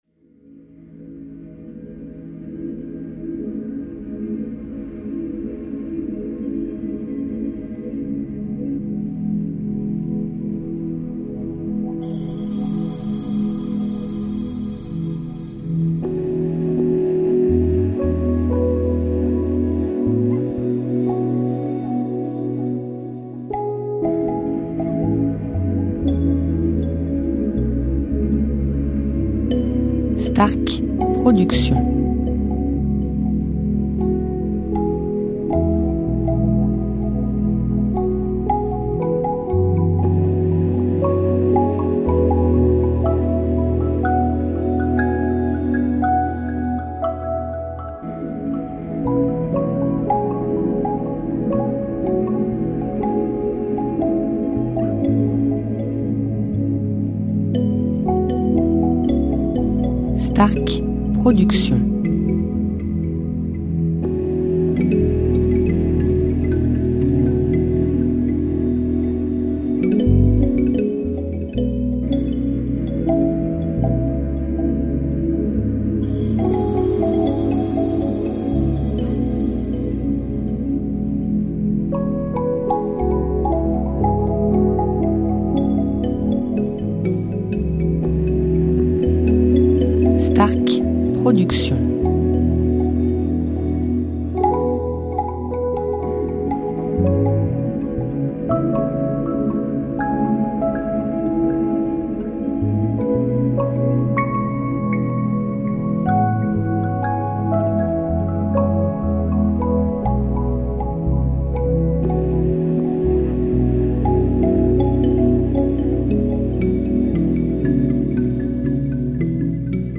style Sophrologie Méditation durée 1 heure